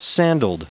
Prononciation du mot sandaled en anglais (fichier audio)
Prononciation du mot : sandaled